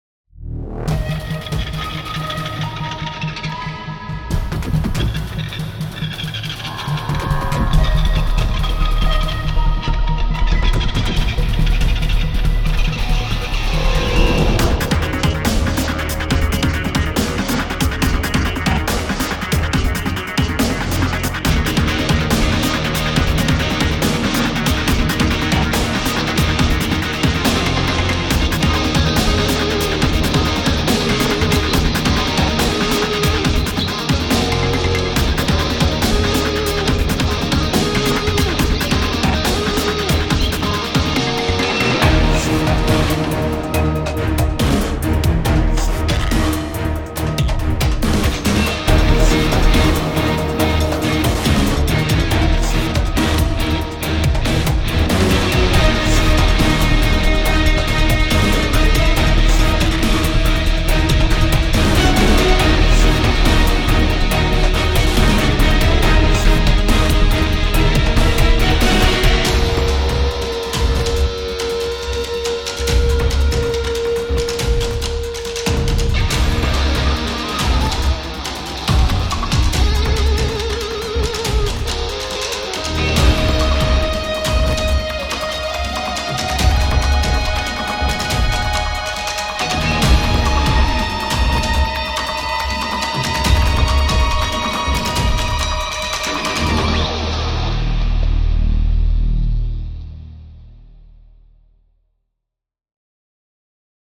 Sci Fi / Action